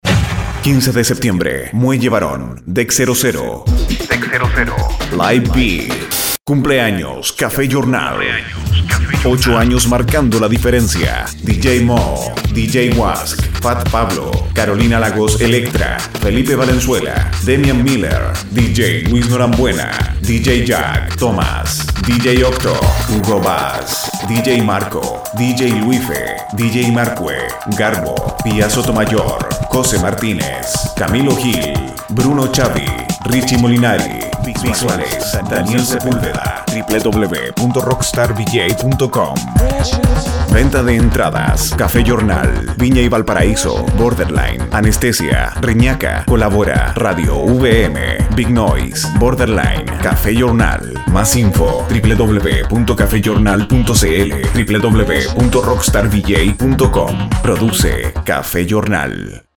Voz en Off